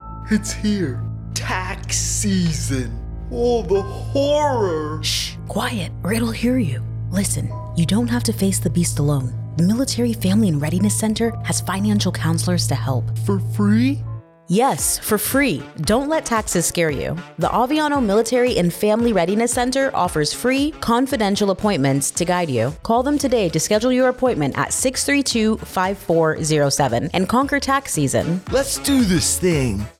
AFN Aviano Radio Spot: MFRC Tax Assistance Appointments